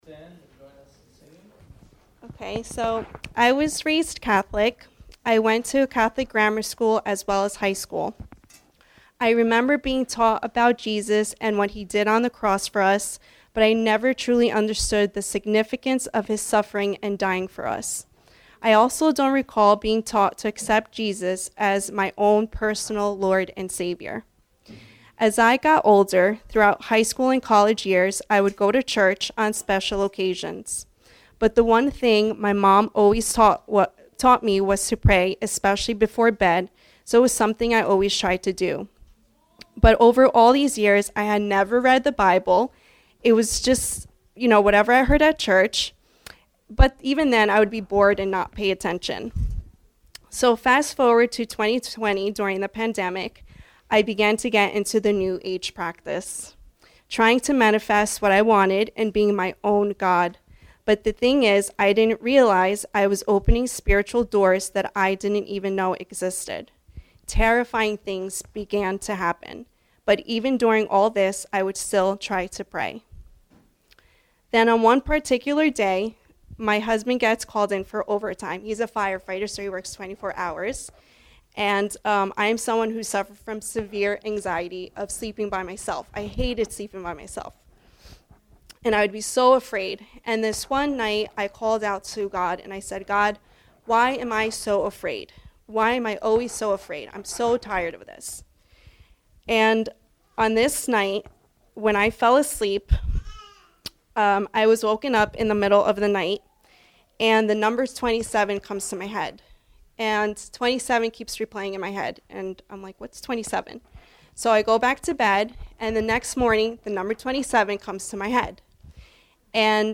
Baptism Testimonies 5/23
Various Speakers | Bread of Life Fellowship
Currently casting to Device Name Two Baptism Testimonies May 2023 Baptism Testimonies 5/23 Various Speakers Bread of Life Fellowship